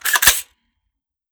Foley / 5.56 M4 Rifle - Cocking Slide 004.wav